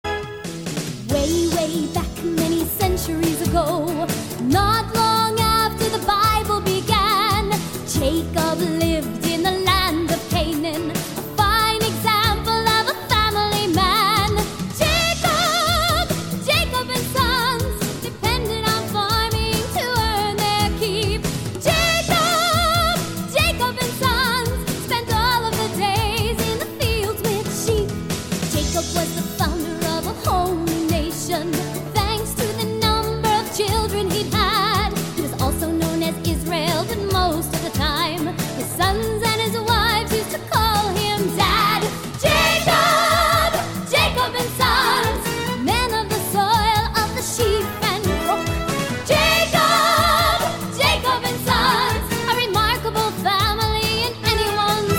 Audition – medium voices – Joseph A to C